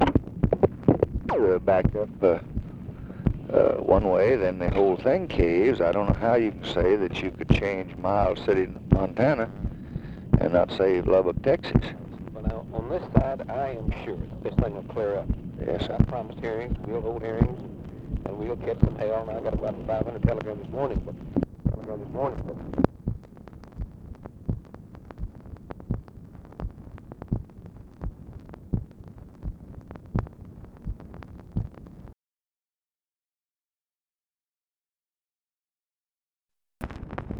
Conversation with OLIN TEAGUE?, January 22, 1965
Secret White House Tapes